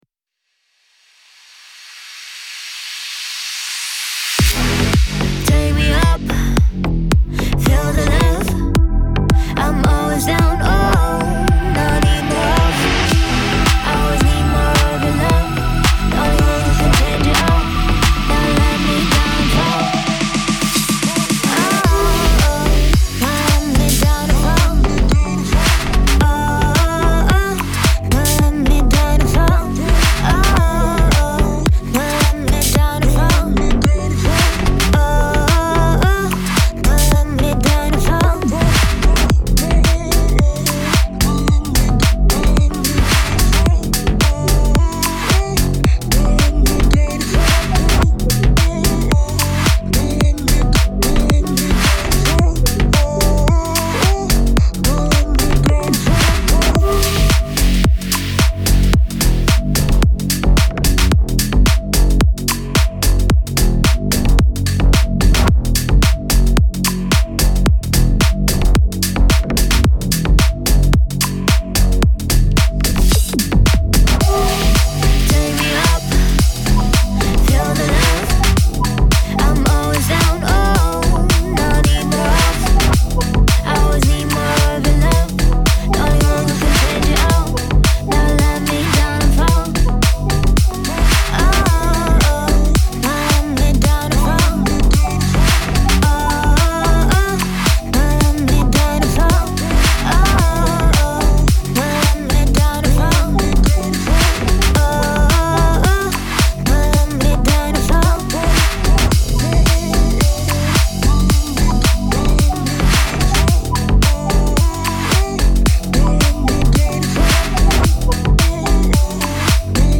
cyberpunk synthwave vaporwave